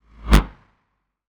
bullet_flyby_deep_02.wav